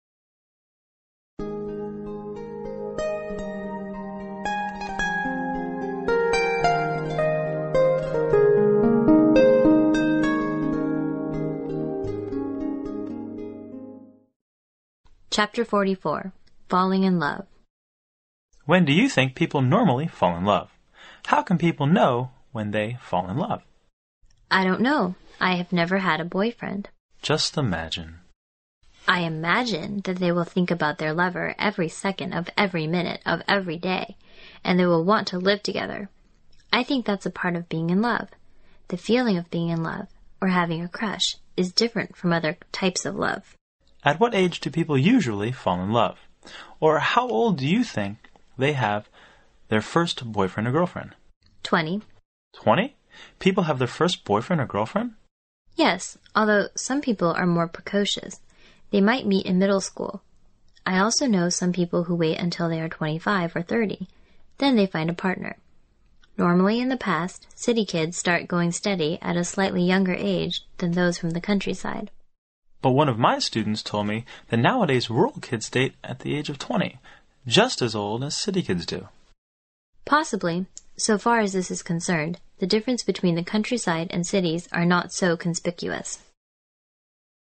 摄取生活原生态，摒弃假性交际，原汁原味的语言素材，习得口语的最佳语境。